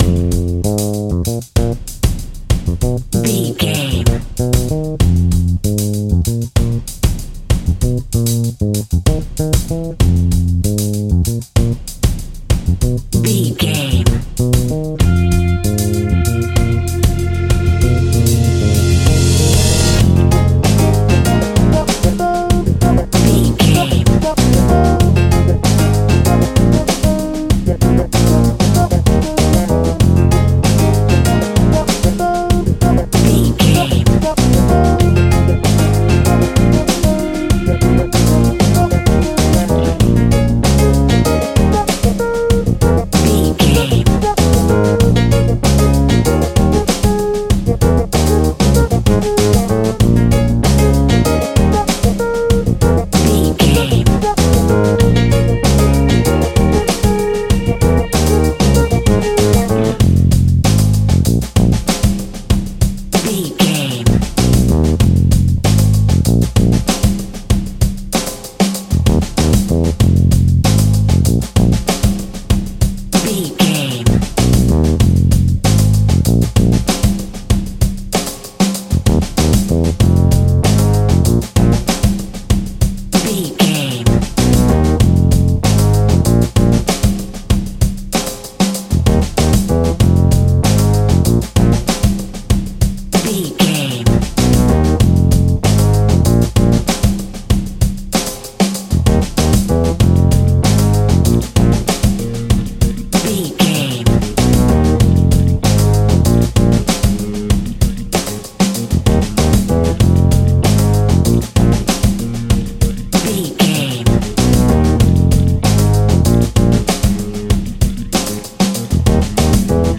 Aeolian/Minor
groovy
lively
electric guitar
electric organ
drums
bass guitar
saxophone
percussion